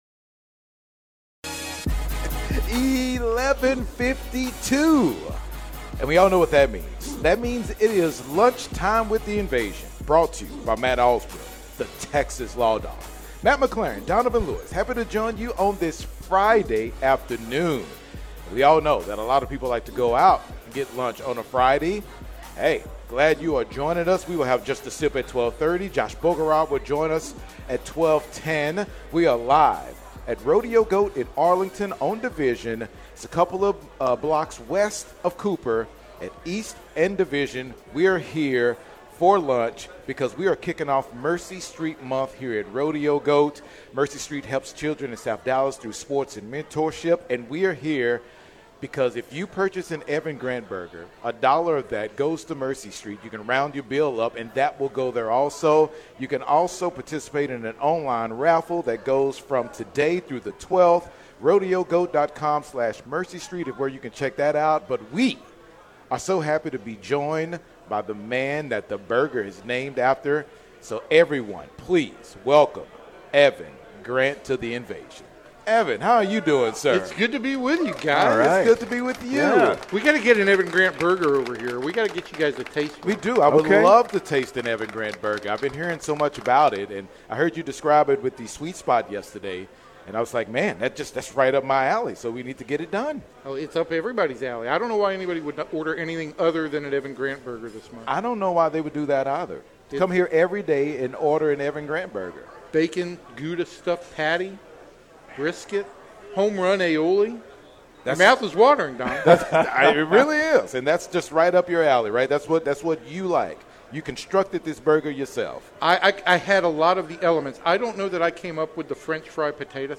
click here A special thank you to 96.7 The Ticket’s The Invasion for broadcasting live from the Arlington location and helping amplify the mission to a broader audience.